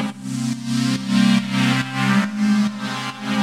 GnS_Pad-alesis1:4_140-C.wav